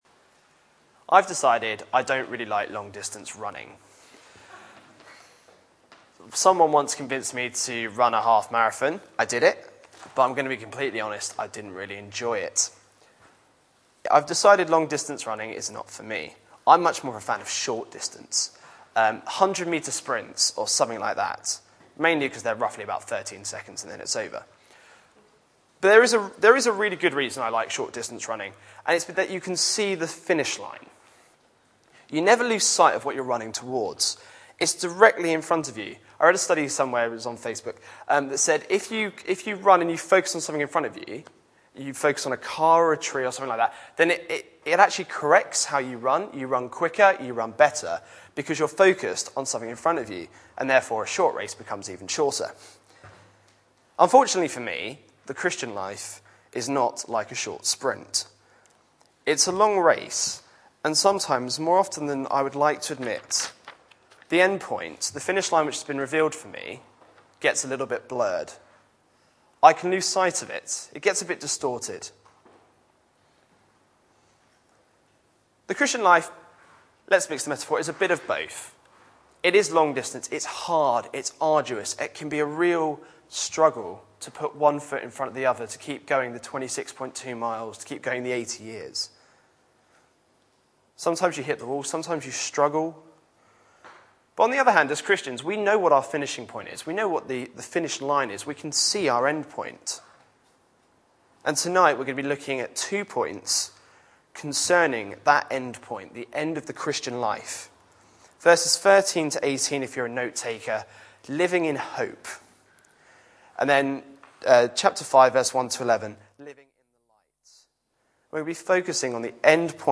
Back to Sermons Living with hope